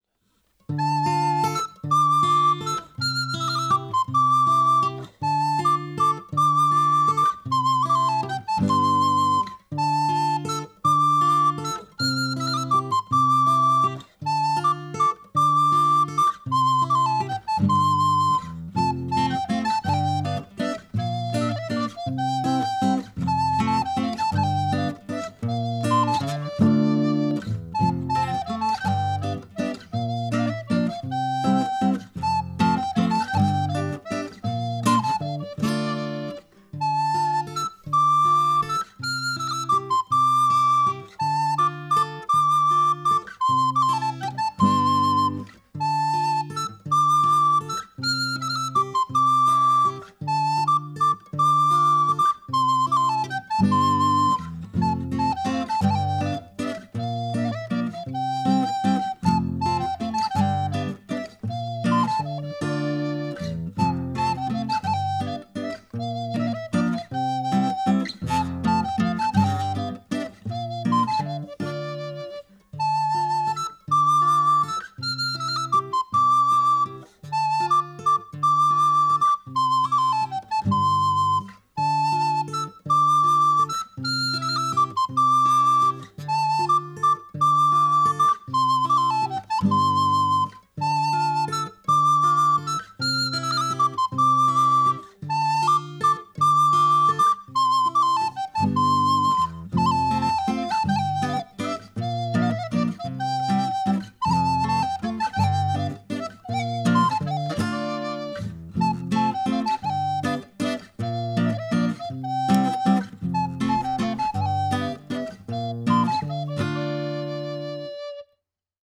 Evolutive Background music